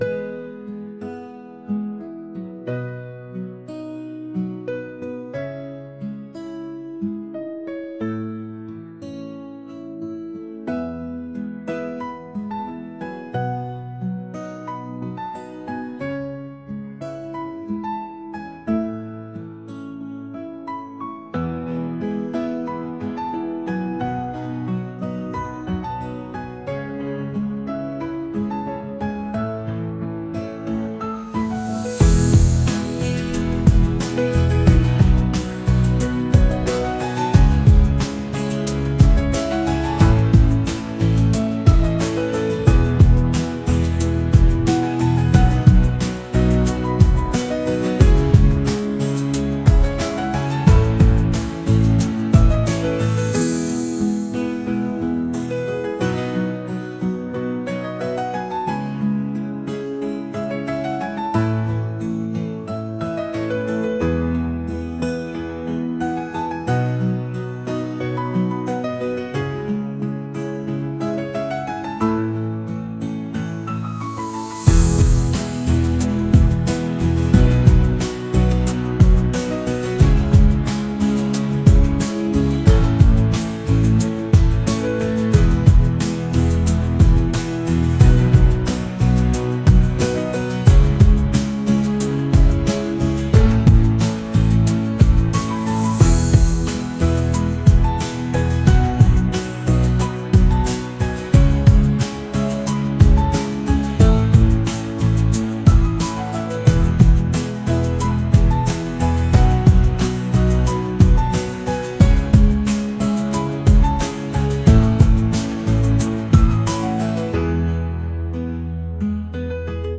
acoustic | ambient | pop